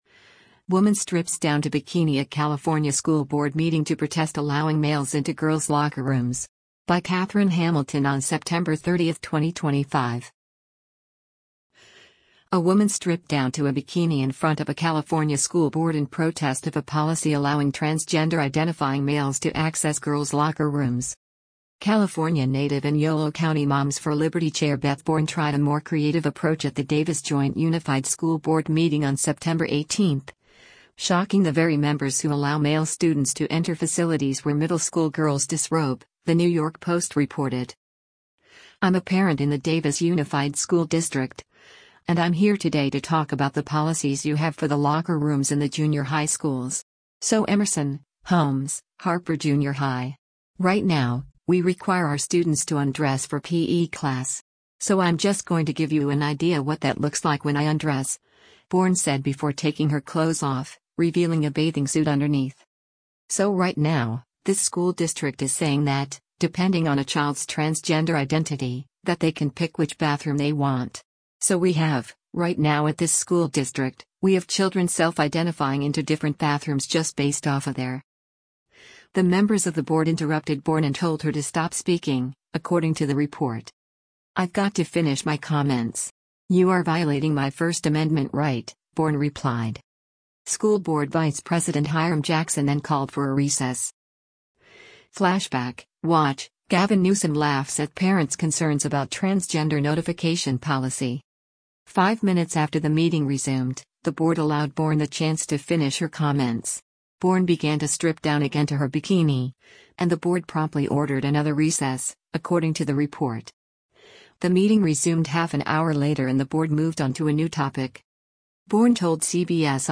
Woman Strips Down to Bikini at California School Board Meeting to Protest Allowing Males into Girls’ Locker Rooms